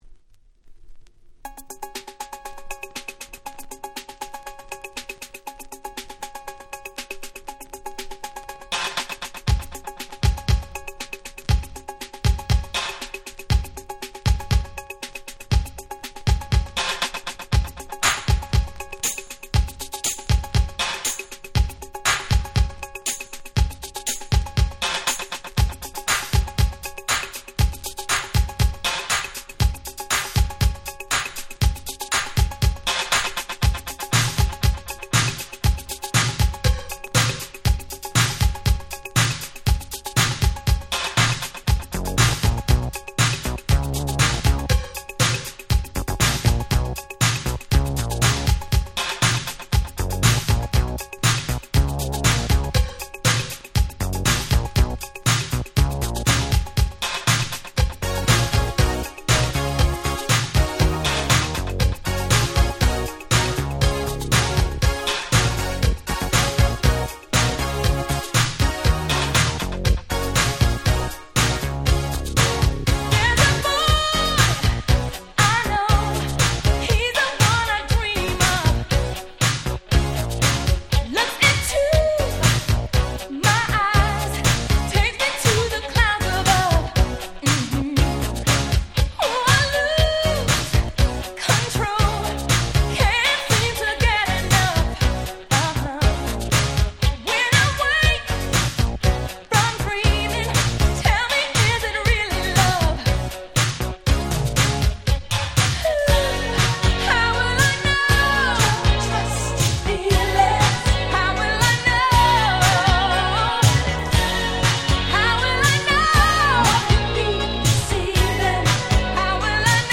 85' Super Hit R&B / Disco !!
80's R&B Classics !!
超キャッチー！！